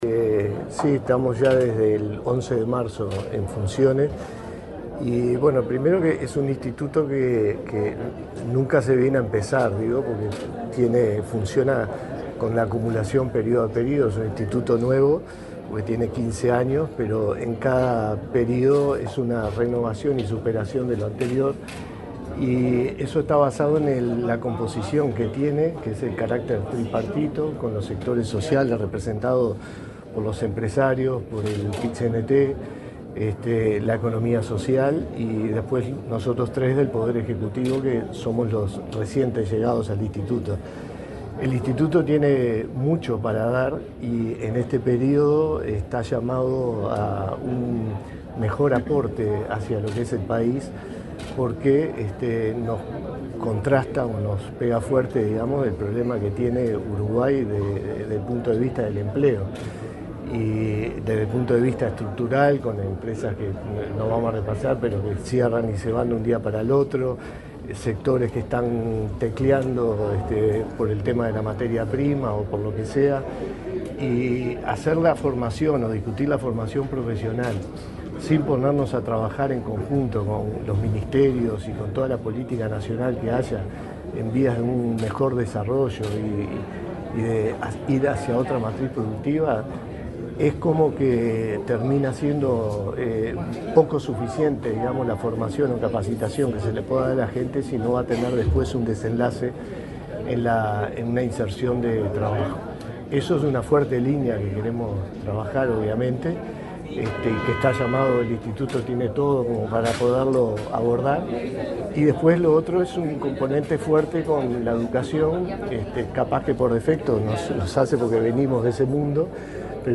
Declaraciones del director general de Inefop, Miguel Venturiello
Este lunes 28, el nuevo director general del Instituto Nacional de Empleo y Formación Profesional (INEFOP), Miguel Venturiello, dialogó con la prensa,